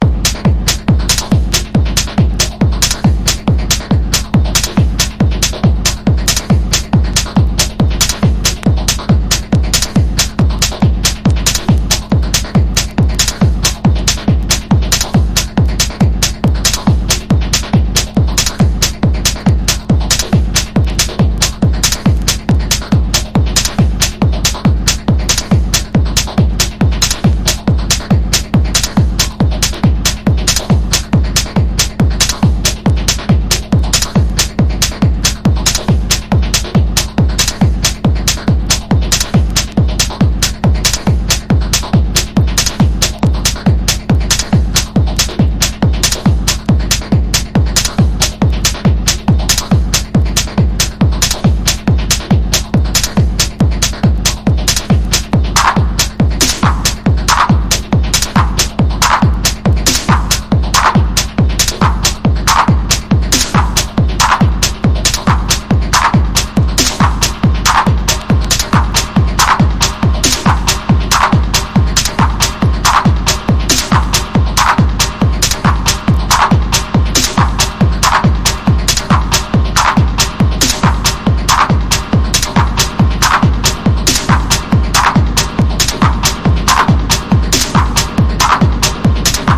ELECTRO HOUSE / TECH HOUSE# TECHNO / DETROIT / CHICAGO